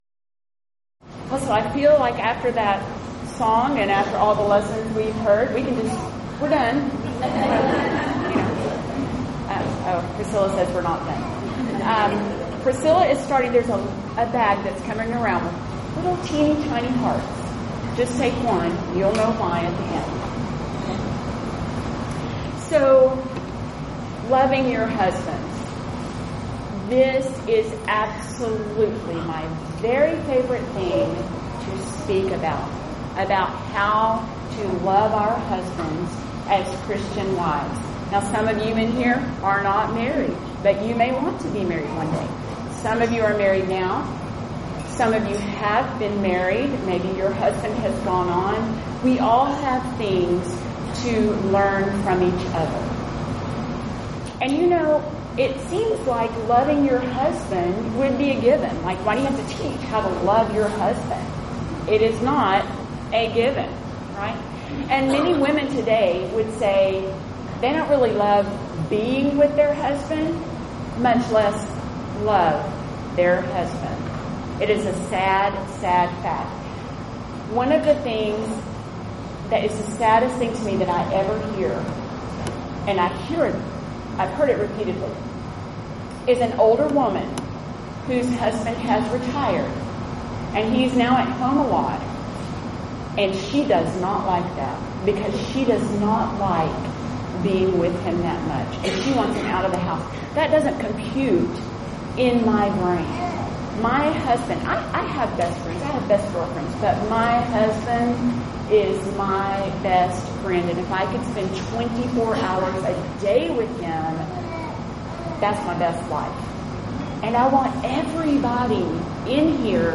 Event: 8th Annual Women of Valor Ladies Retreat
Ladies Sessions